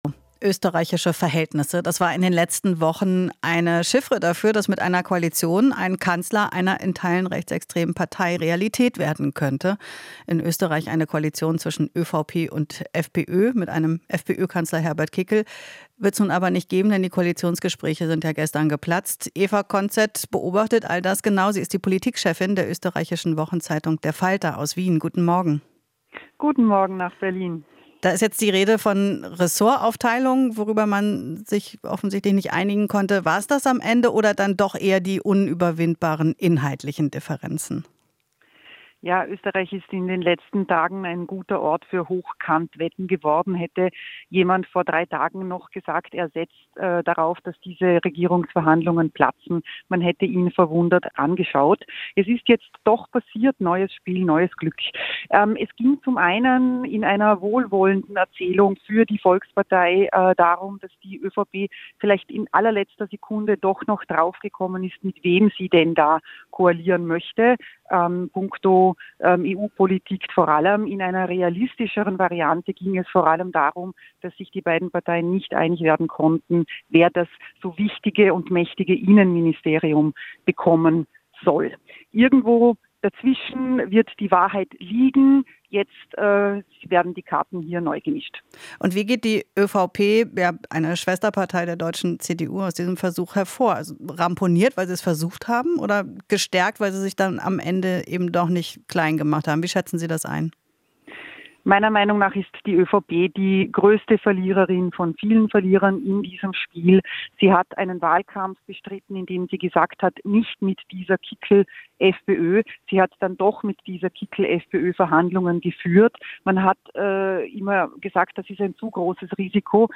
Interview - Gescheiterte FPÖ/ÖVP-Koalition: Ende eines "unwürdigen Schauspiels"